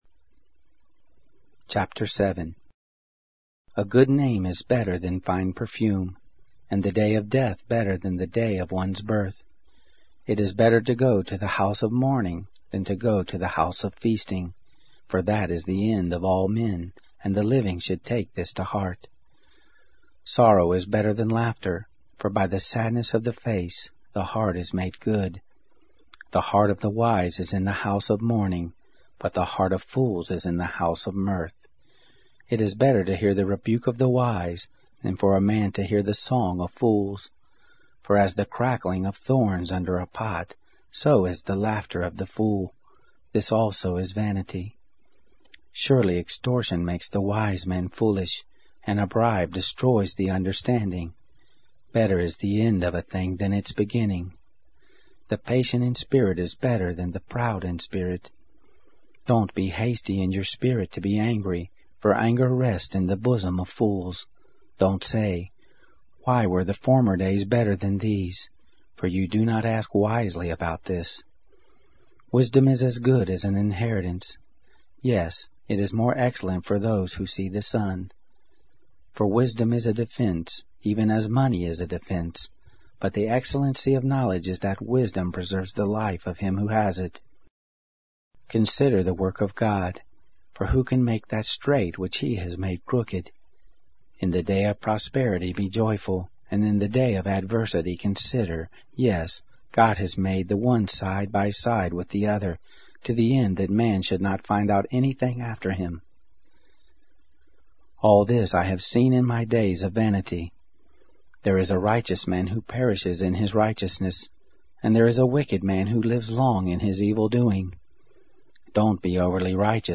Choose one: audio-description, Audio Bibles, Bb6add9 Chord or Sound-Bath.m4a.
Audio Bibles